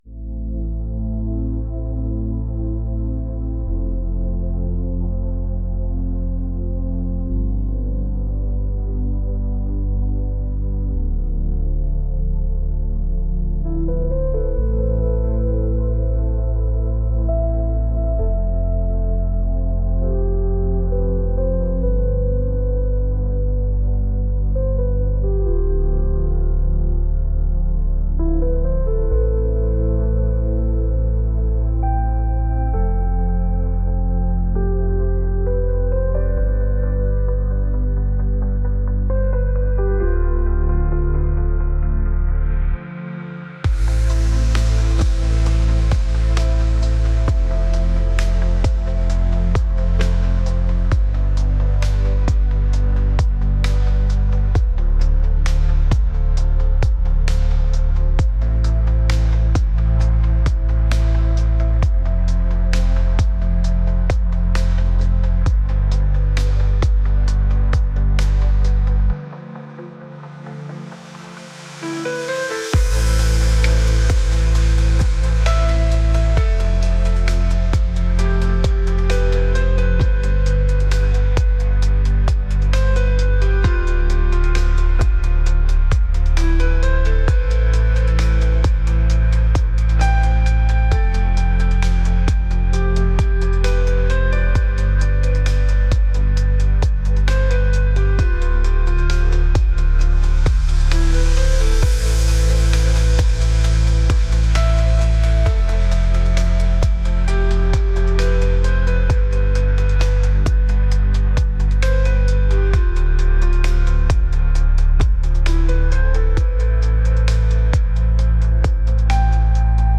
dreamy | atmospheric | electronic